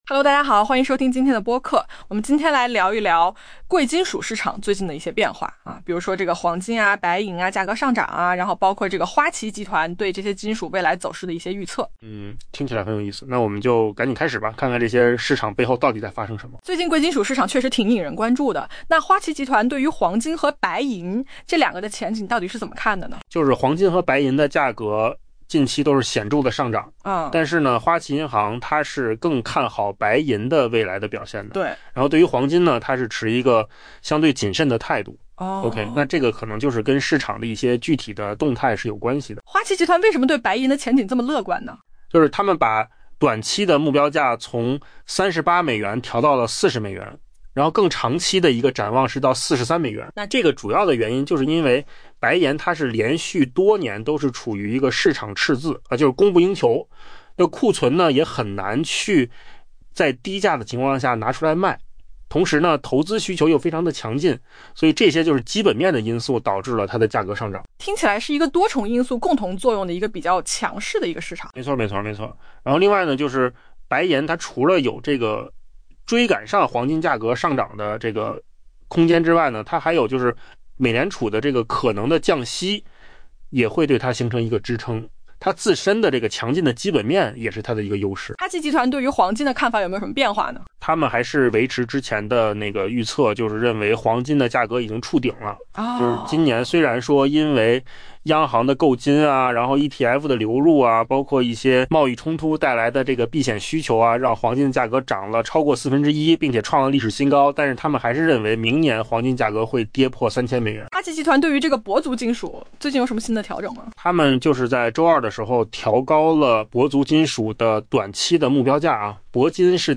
AI播客：换个方式听新闻 下载mp3